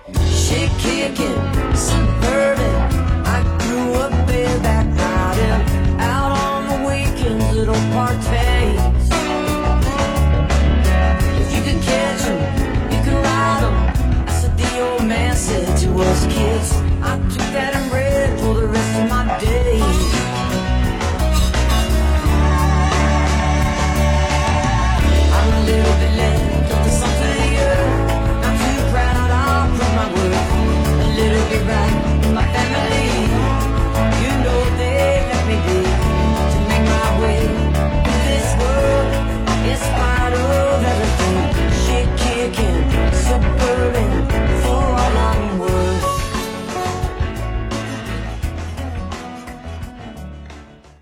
(captured from the online stream)